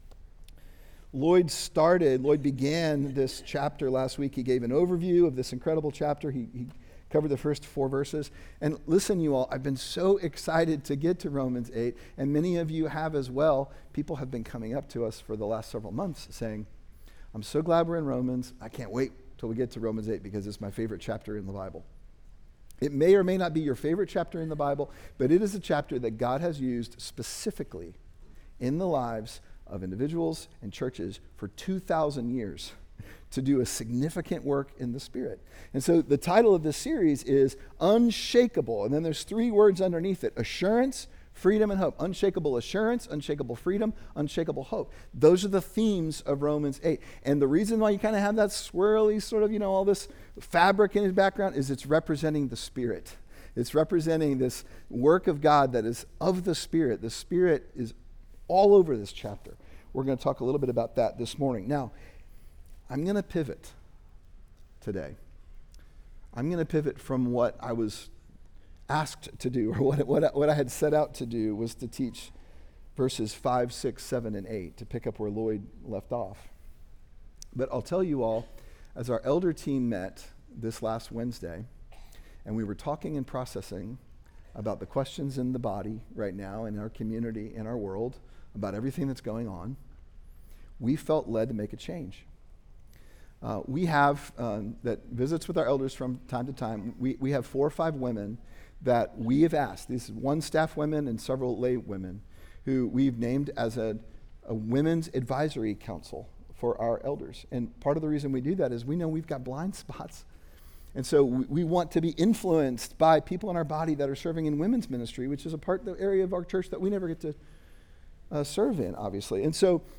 Sermon Unshakeable: Romans 8